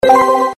02 Shutter Sound 02.mp3